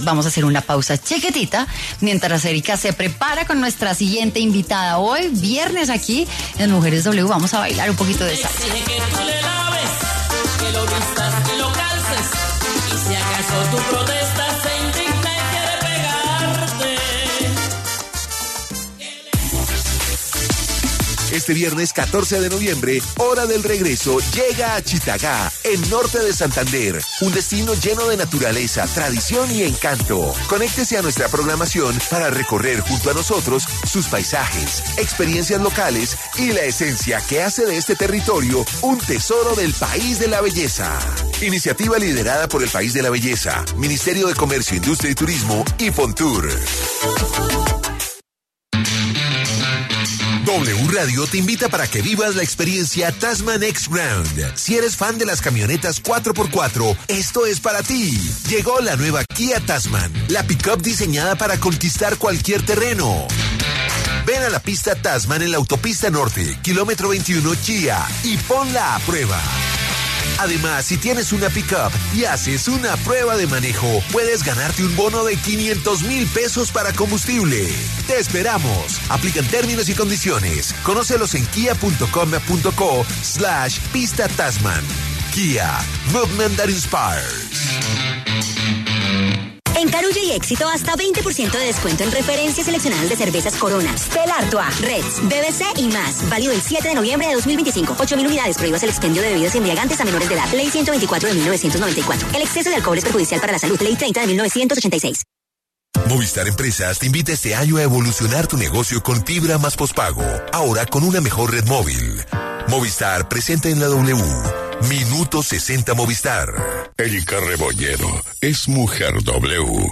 En entrevista con Mujeres W